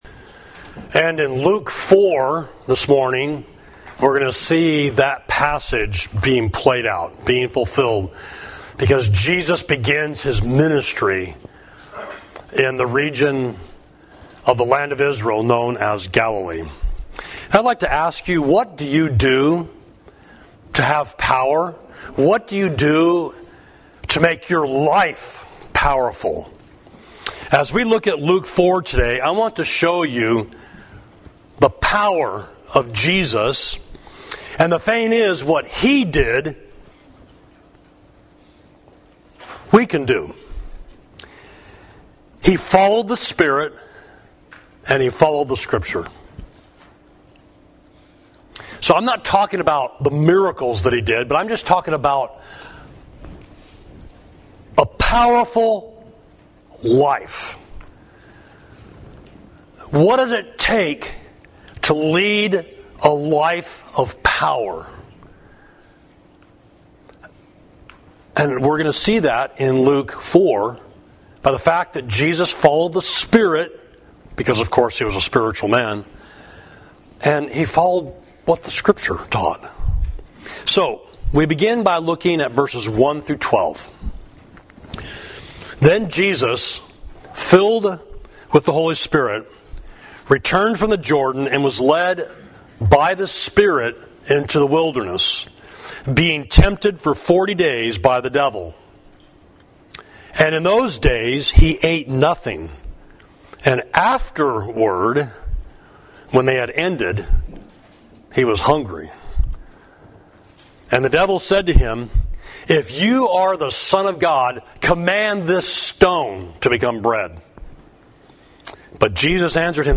Sermon: What Made the Ministry of Jesus Powerful?